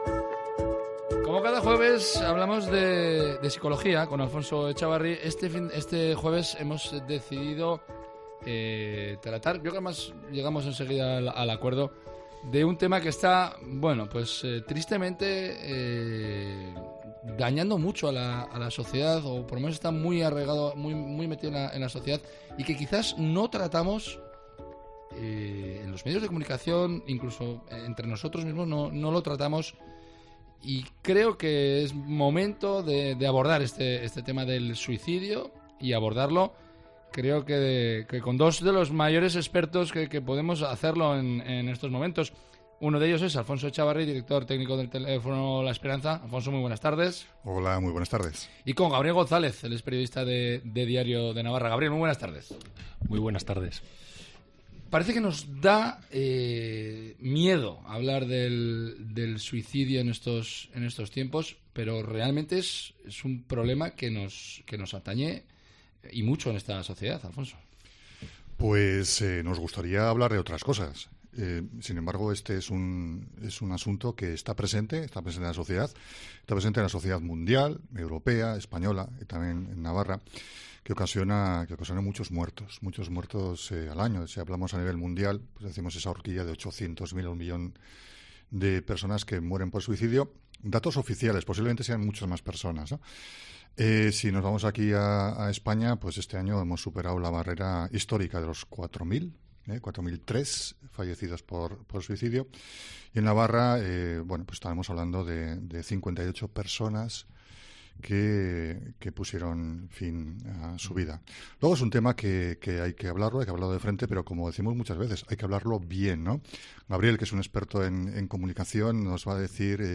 En los micrófonos de Cope Navarra han indicado que hay que intentar quitar el tabú y el estigma de estos sucesos. Y es que el suicidio ha sido la causa de muerte de más de 4.000 personas en España en el último año.